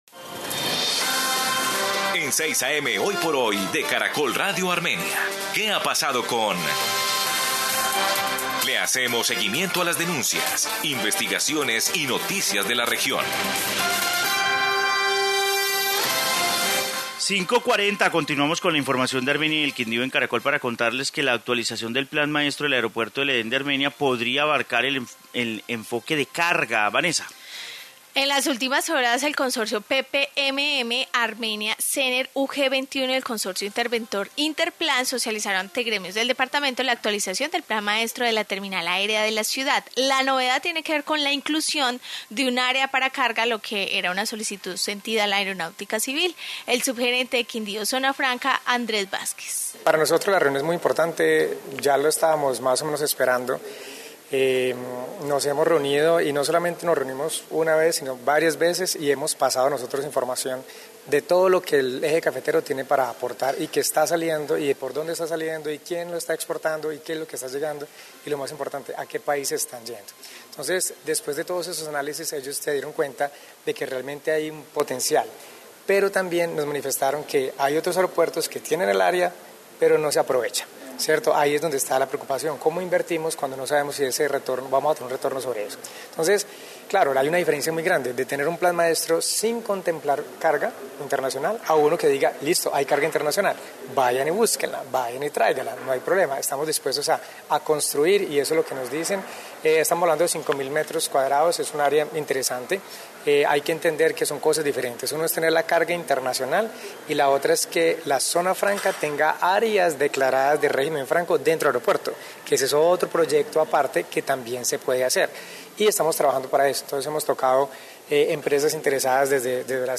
Informe plan maestro aeropuerto de Armenia